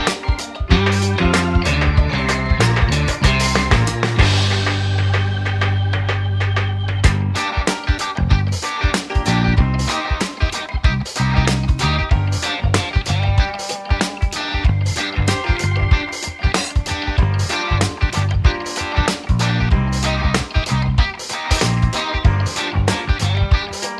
no Backing Vocals Soft Rock 4:21 Buy £1.50